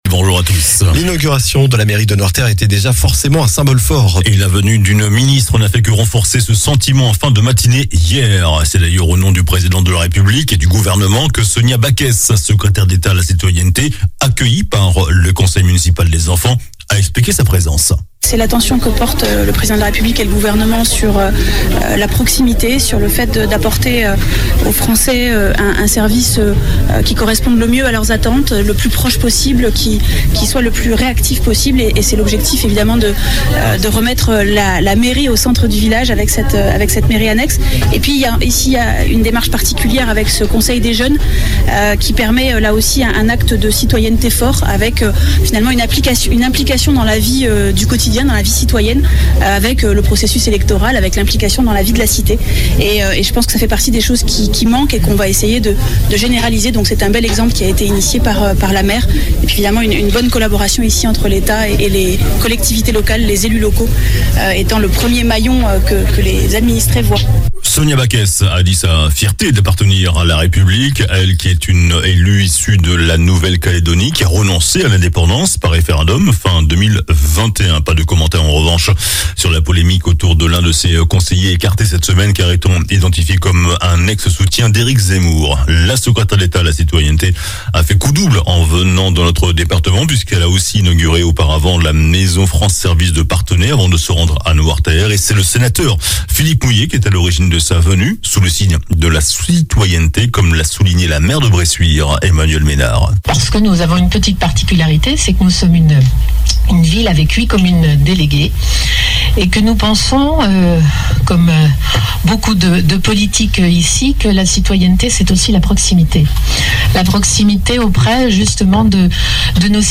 JOURNAL DU SAMEDI 10 SEPTEMBRE